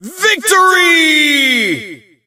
victory_vo_01.ogg